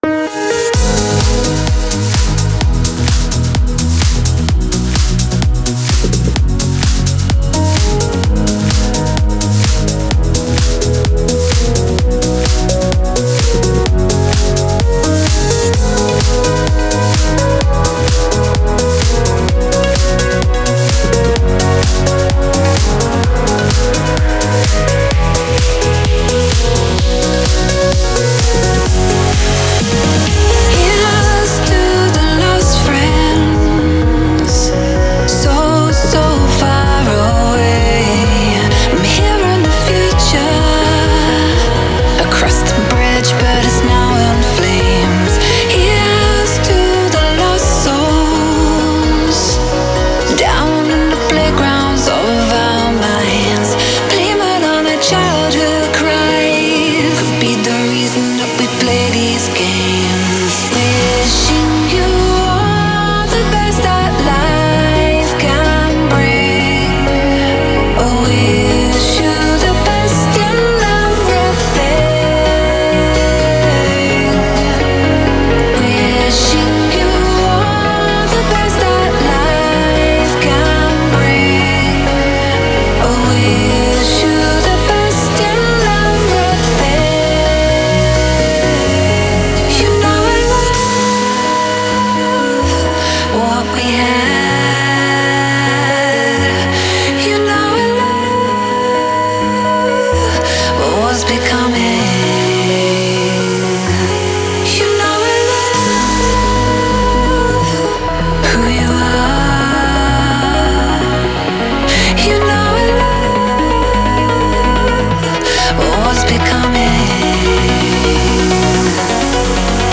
это трек в жанре EDM с элементами прогрессивного хауса